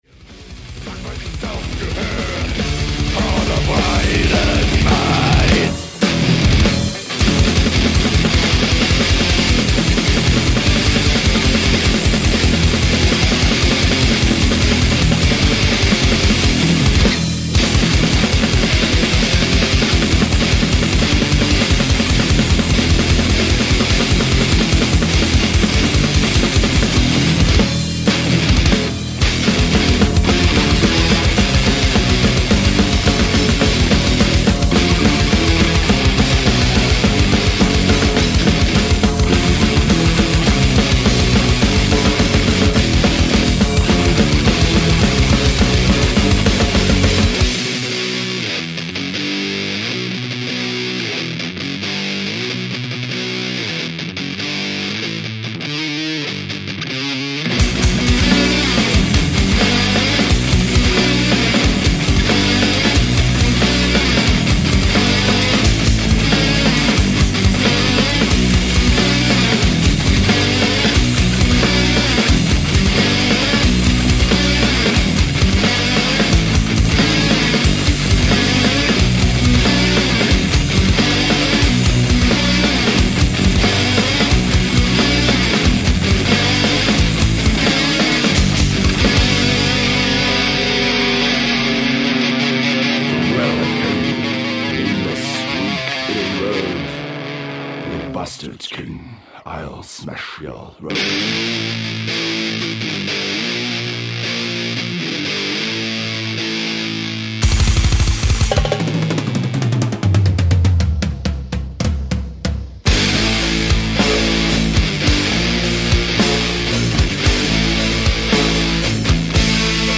guitars/vocals
bass
drums